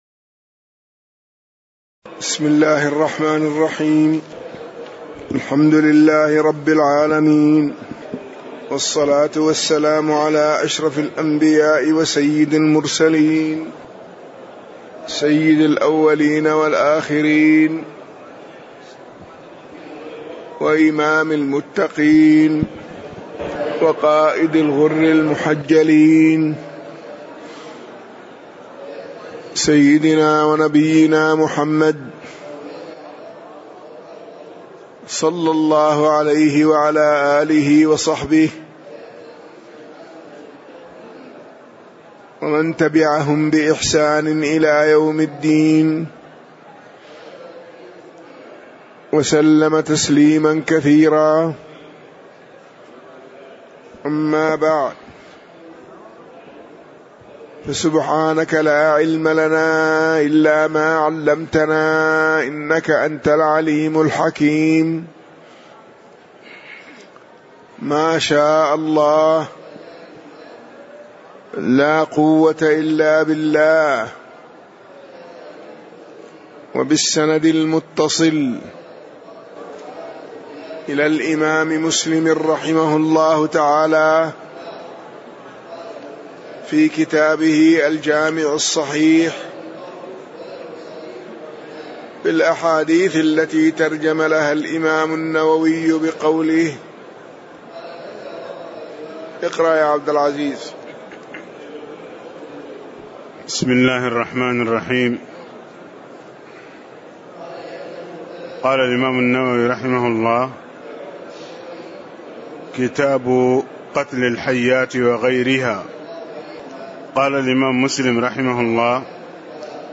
تاريخ النشر ٢٣ ربيع الأول ١٤٣٧ هـ المكان: المسجد النبوي الشيخ